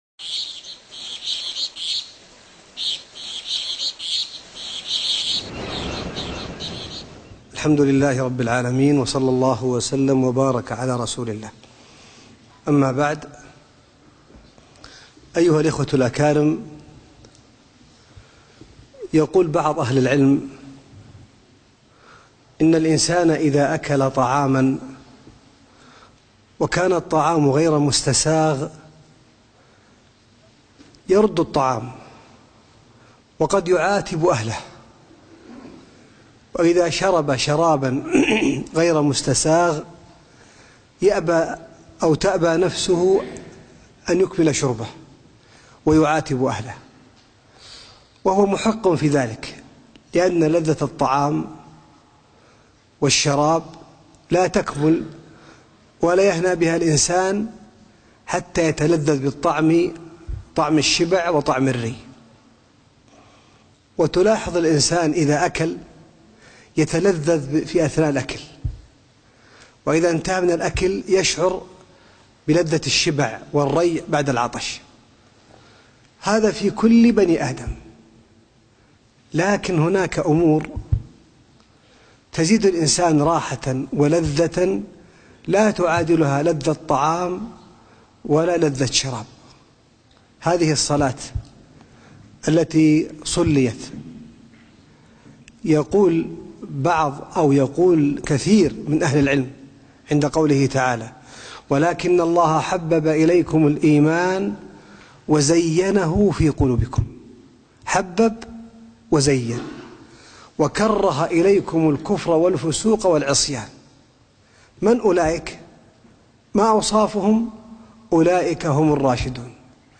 التلذذ بالعبادة - كلمة